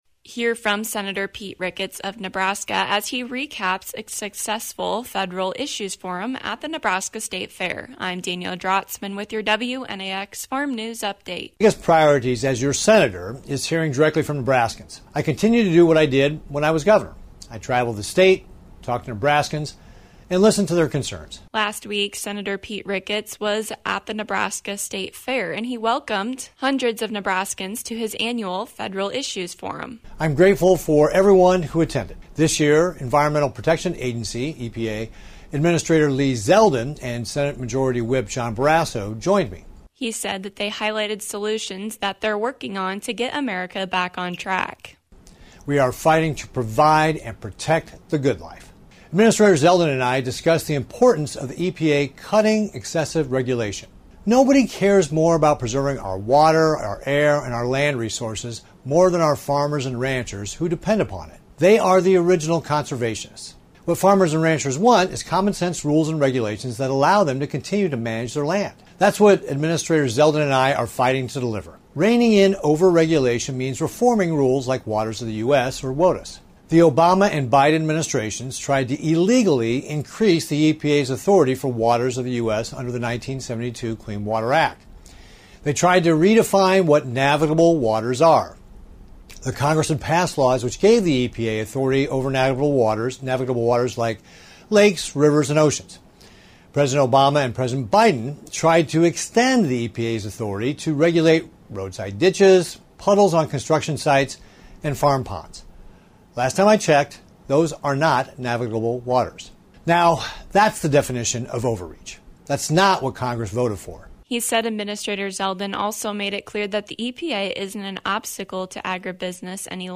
Hear from Senator Pete Ricketts as he recaps his federal issues forum at the Nebraska State Fair.